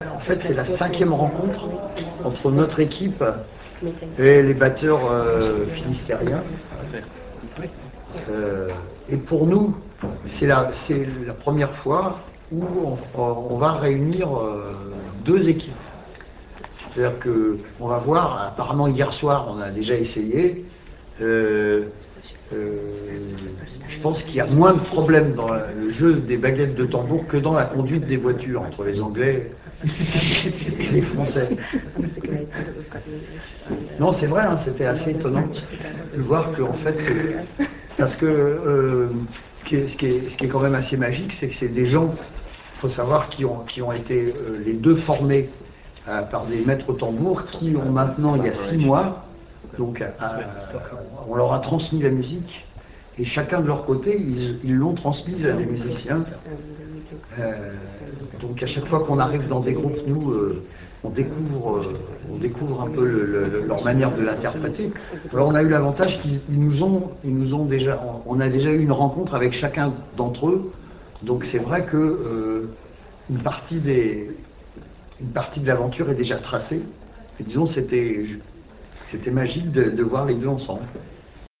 40 batteurs finistériens
Rencontre de tambours.
En prélude à cet événement, se retrouveront à Plougastel les 40 batteurs anglais (Stockton/Newcastle) et les 40 batteurs finistériens.
rencontre_batteurs.ra